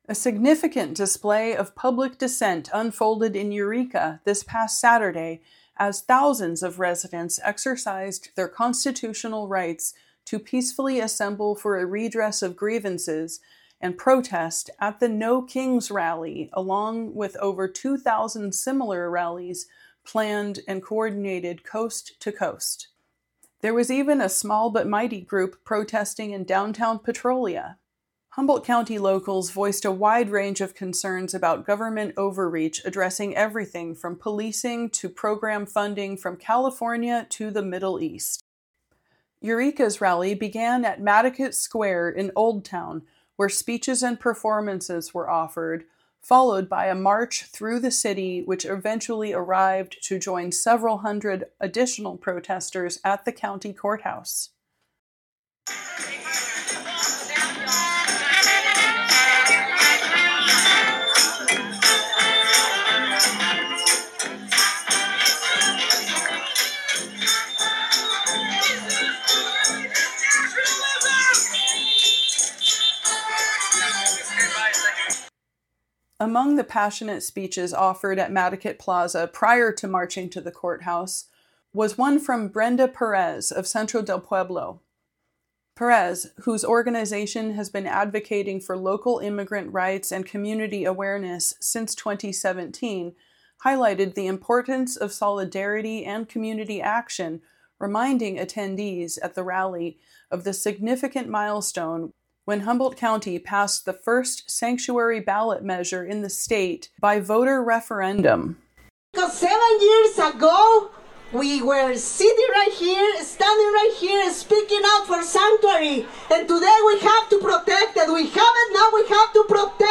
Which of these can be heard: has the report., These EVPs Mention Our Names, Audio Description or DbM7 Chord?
has the report.